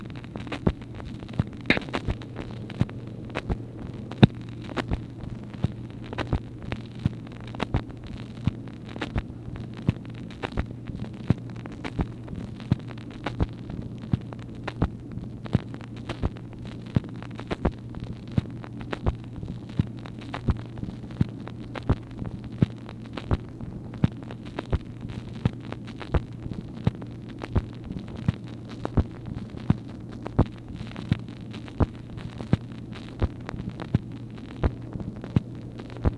MACHINE NOISE, May 8, 1964
Secret White House Tapes | Lyndon B. Johnson Presidency